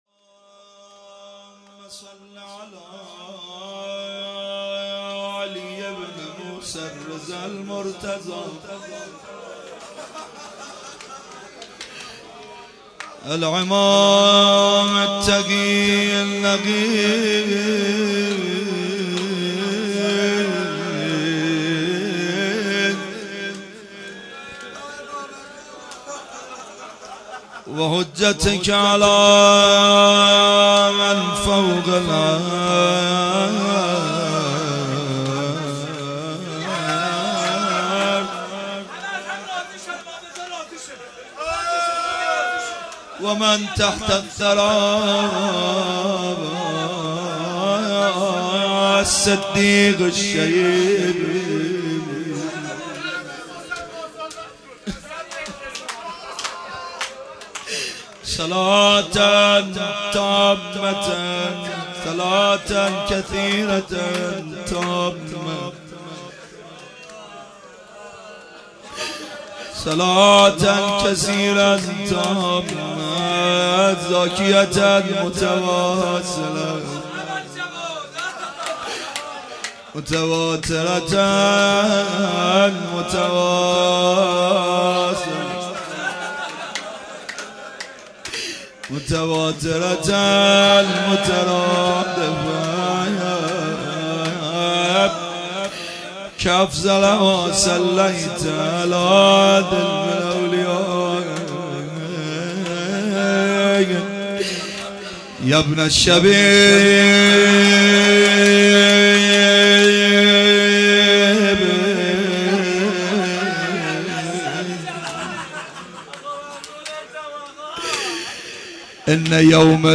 قالب : روضه